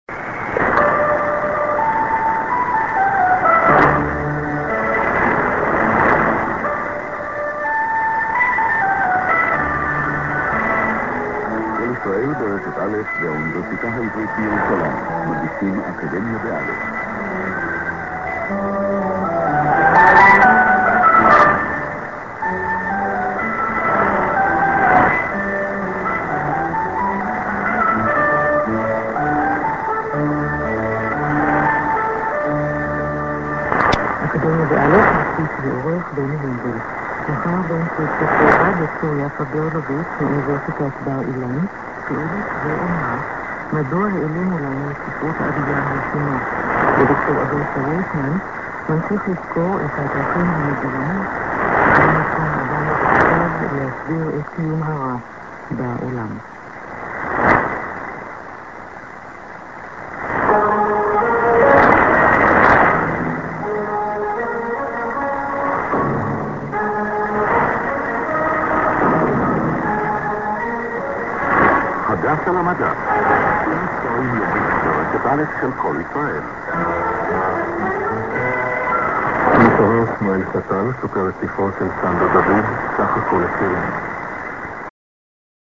St. music->ID(man)->ANN(women)->1'15":ID(man)